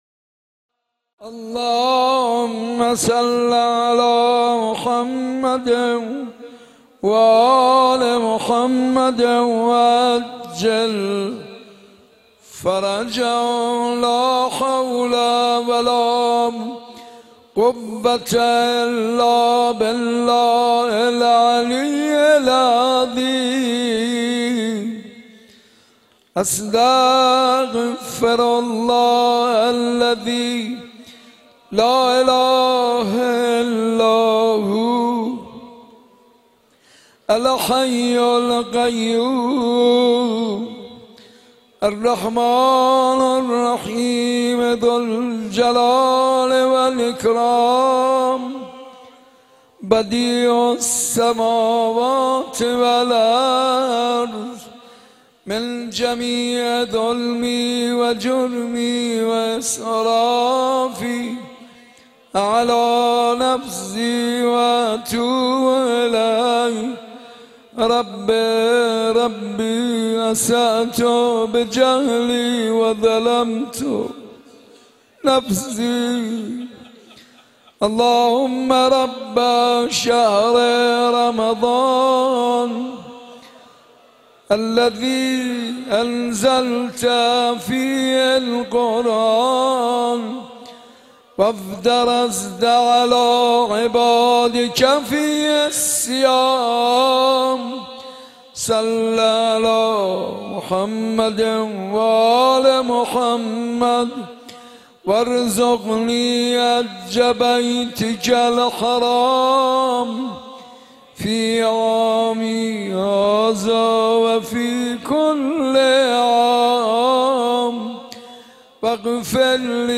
عقیق: مراسم شب اول ماه مبارک رمضان باحضور گسترده مردم شب زنده دار در مسجد ارک برگزار شد.